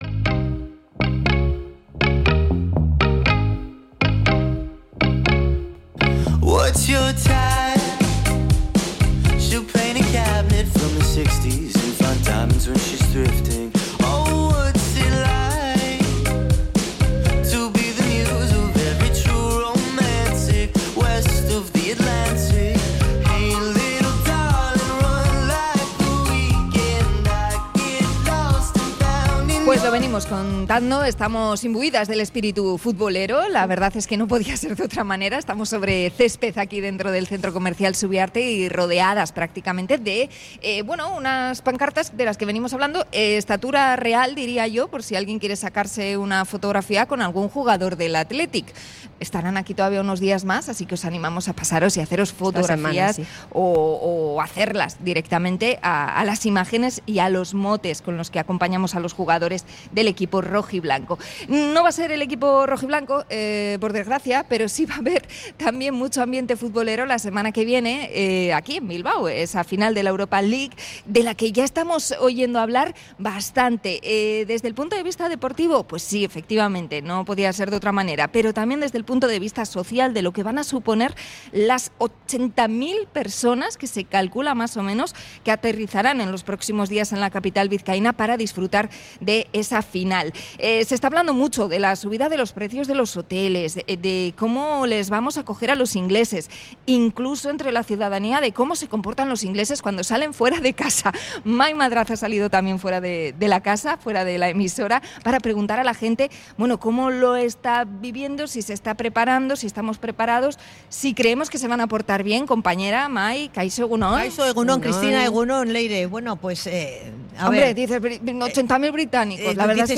Vecinos de Bilbao dan su opinión sobre la final de la Europa League
ENCUESTA-INVASION-BRITANICA.mp3